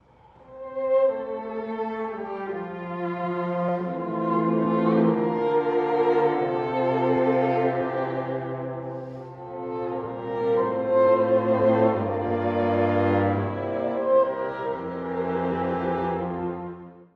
↑古い録音のため聴きづらいかもしれません！（以下同様）
全員のユニゾンで、祈るように始まります。